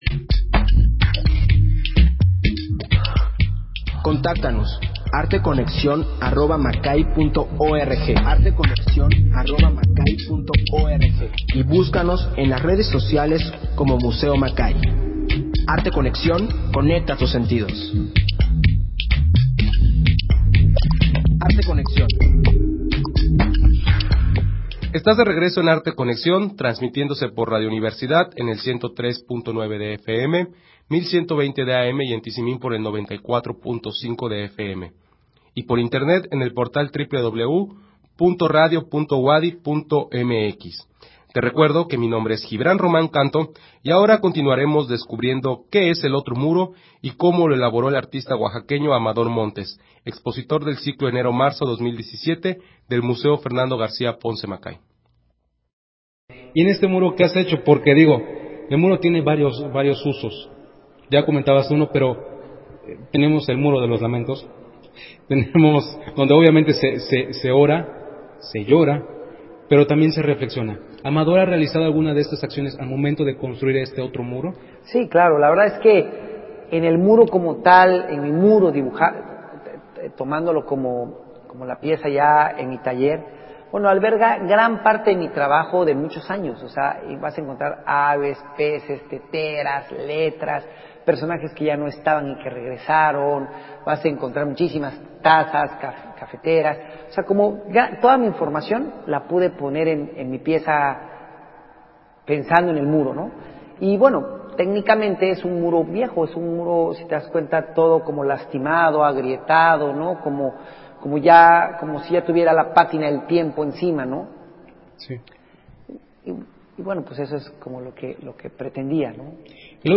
Emisión de Arte Conexión transmitida el 23 de febrero del 2017.